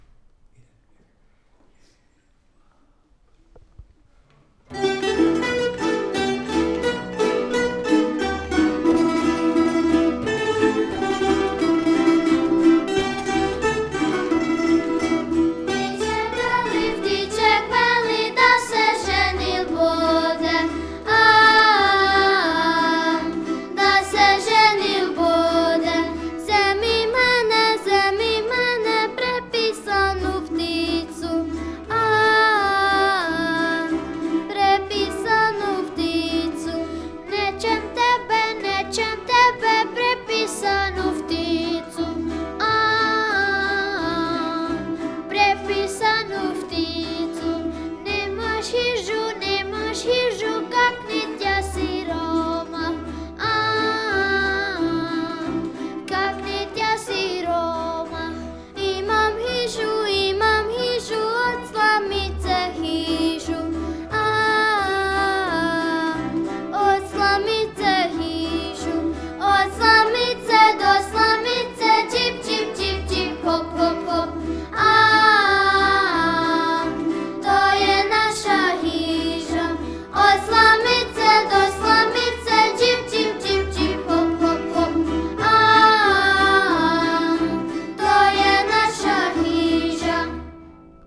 Odličan nastup naše mješovite pjevačke skupine